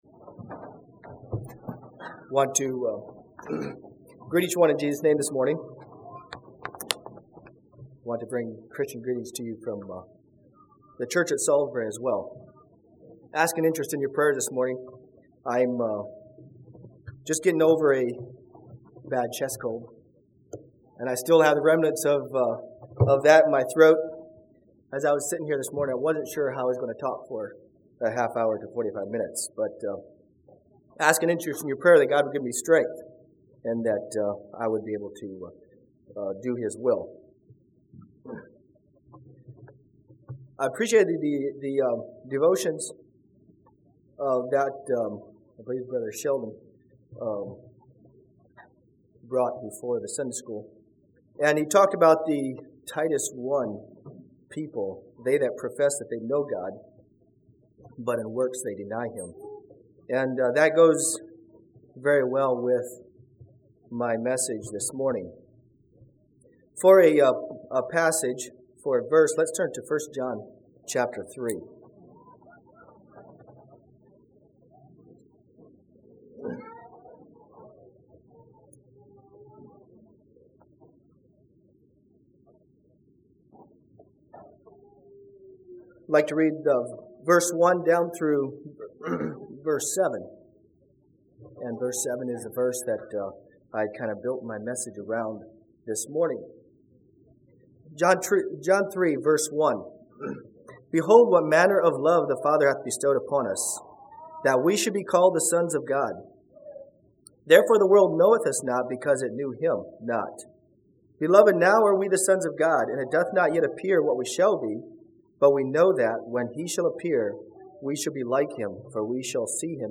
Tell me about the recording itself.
Congregation: Piedmont Speaker